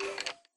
skeleton3.ogg